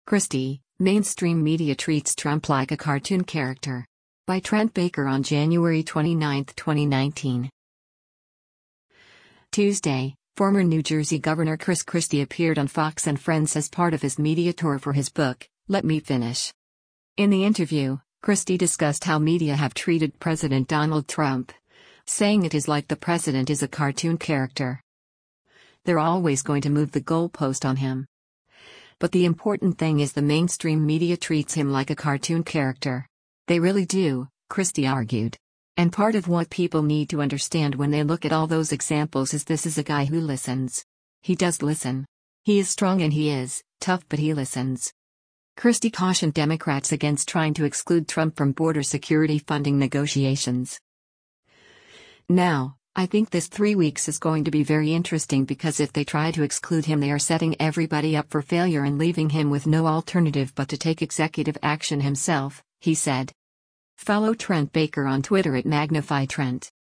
Tuesday, former New Jersey governor Chris Christie appeared on “Fox & Friends” as part of his media tour for his book, “Let Me Finish.”
In the interview, Christie discussed how media have treated President Donald Trump, saying it is like the president is “a cartoon character.”